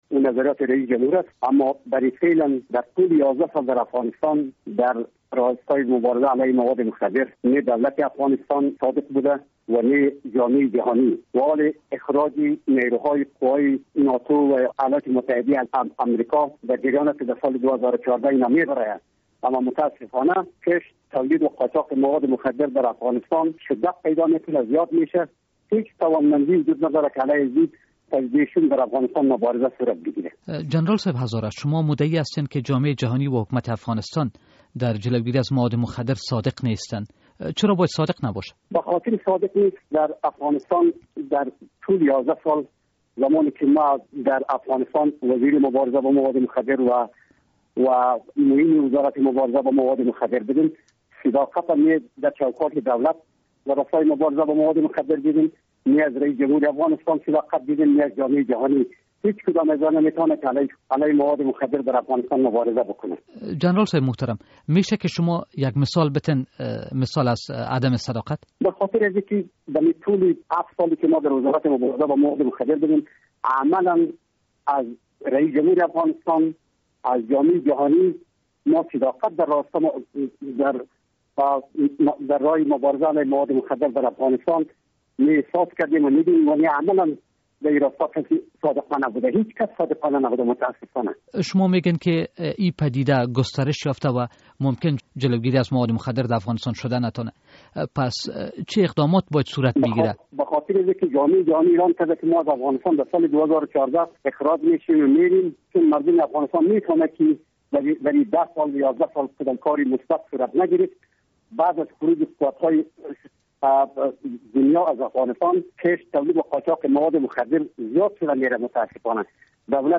مصاحبه در مورد تعهد جدید حکومت در امر مبارزه با مواد مخدر